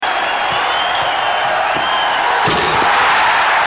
Slam Dunk and crowd
Tags: Domain Auction Domain names auction sounds Basketball hoops